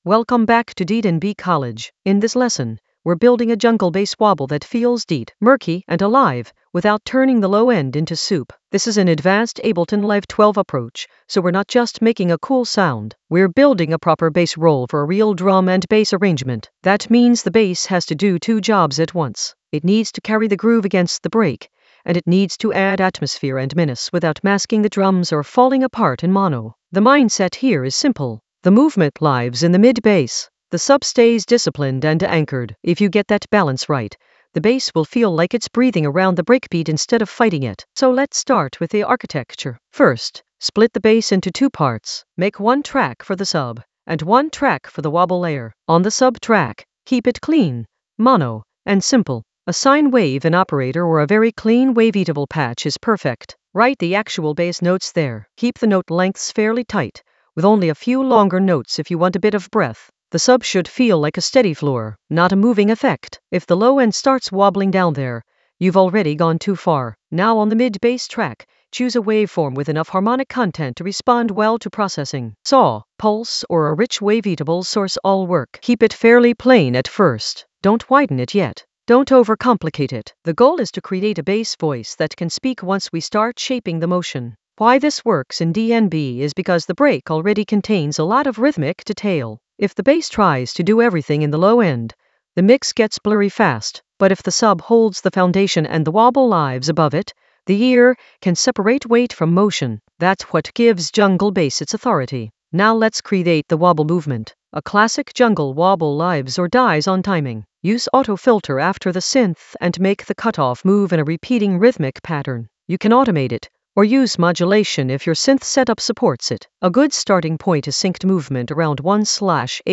An AI-generated advanced Ableton lesson focused on Arrange a jungle bass wobble for deep jungle atmosphere in Ableton Live 12 in the Mixing area of drum and bass production.
Narrated lesson audio
The voice track includes the tutorial plus extra teacher commentary.